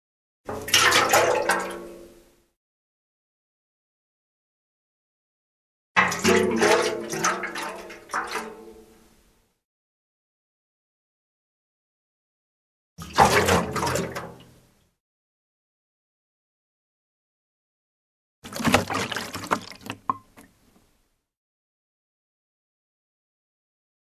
Звуки канистры
Звук Откручиваем крышку у металлической канистры (потом закручиваем) (00:17)